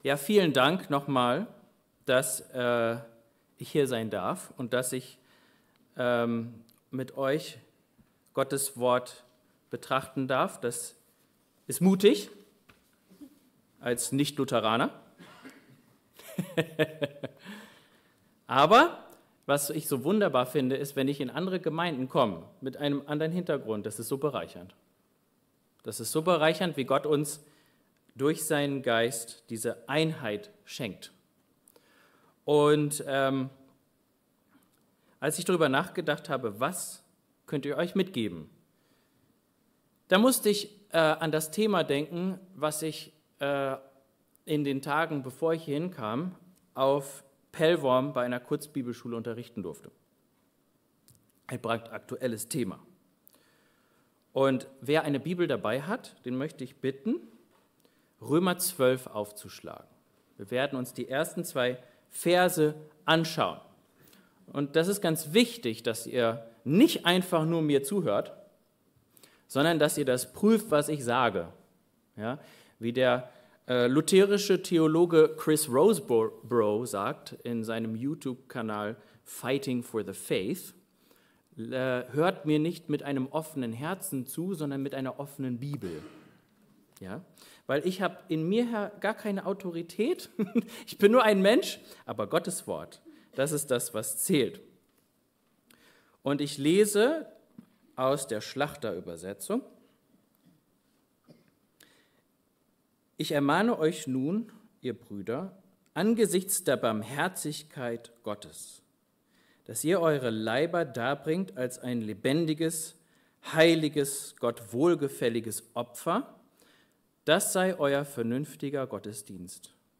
Passage: Römer 12, 1-2 Dienstart: Gottesdienst